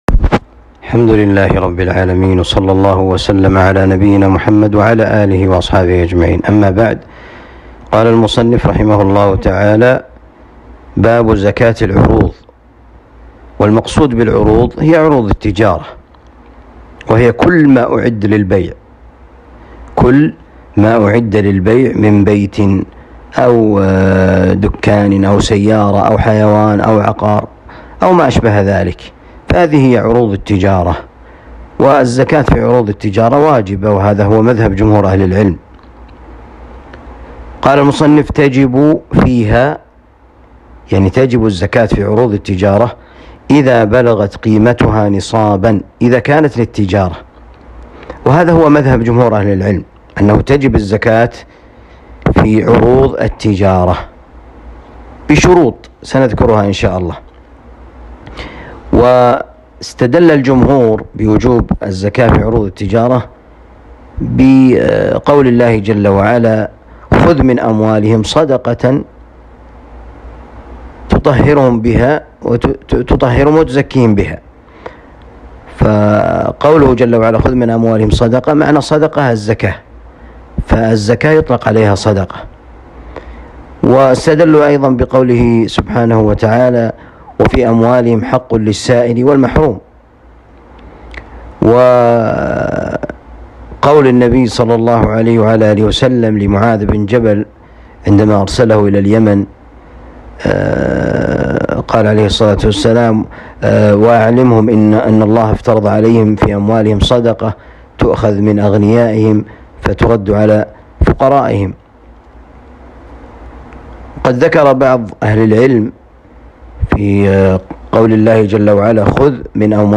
الدرس الخامس والثلاثون